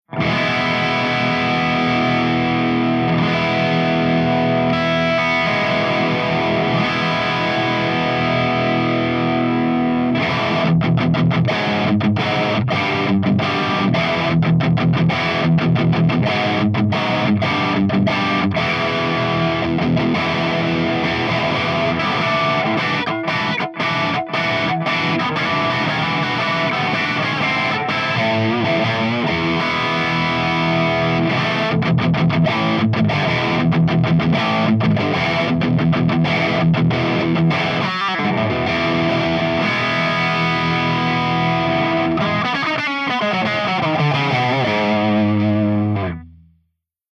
149_MESA SINGLE RECTIFIER_CH2CRUNCH_V30_P90